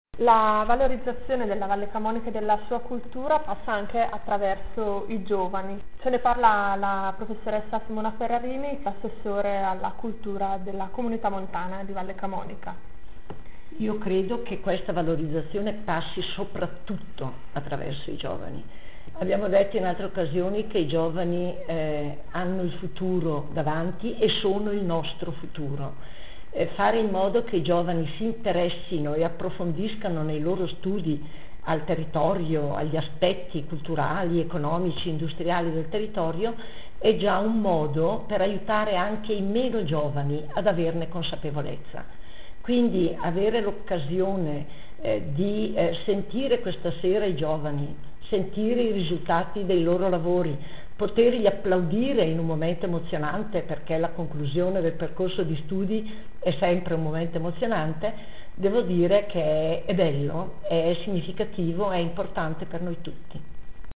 Ascolta l'intervista all'assessore Simona Ferrarini (Scarica il file mp3)